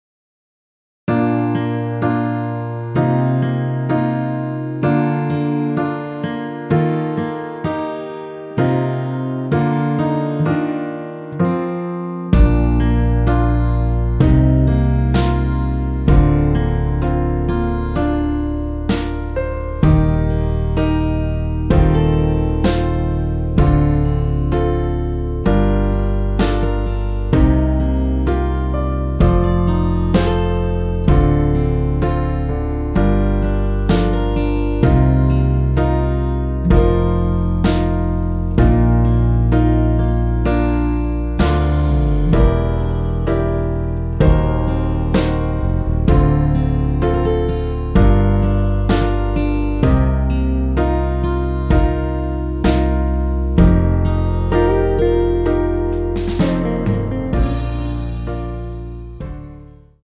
남성분이 부르실 수 있는 키의 MR입니다.
원키에서(-5)내린 MR입니다.
Bb
앞부분30초, 뒷부분30초씩 편집해서 올려 드리고 있습니다.